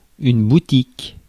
Ääntäminen
boutique {f} Île-de-France, France